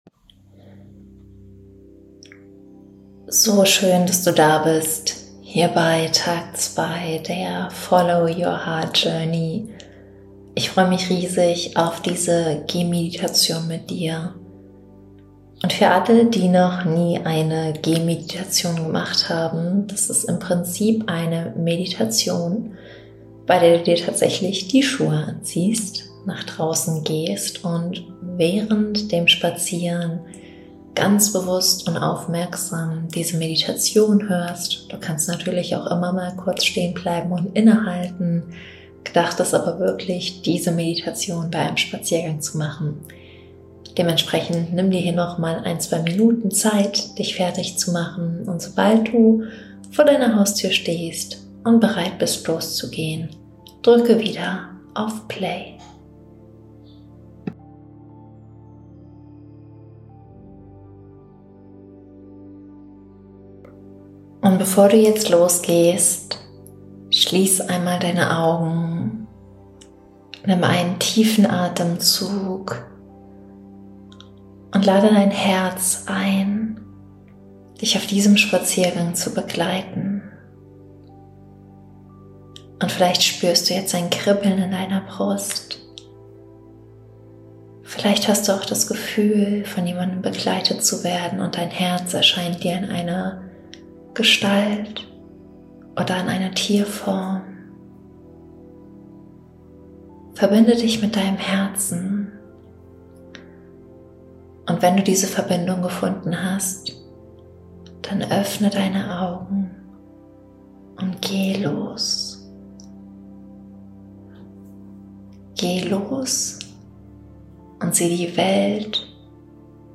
Meditation
FYHJ_Day2_Meditation.mp3